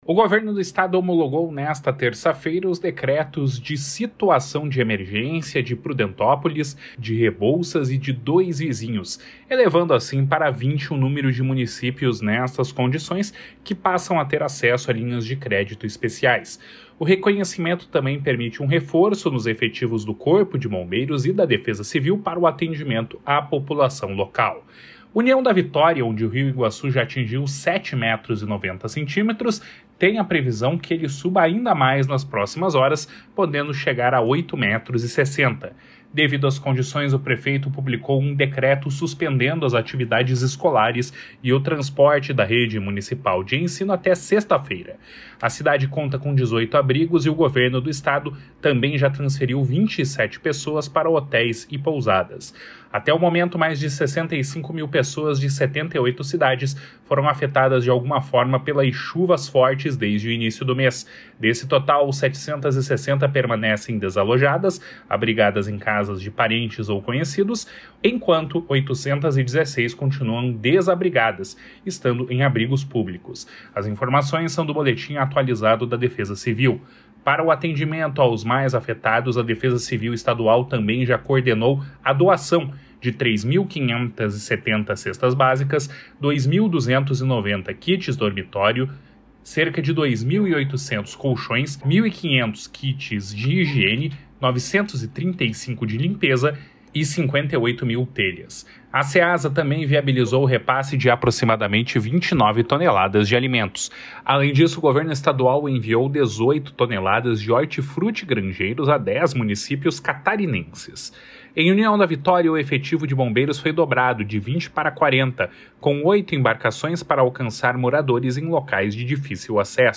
Boletim Defesa Civil 17-10.mp3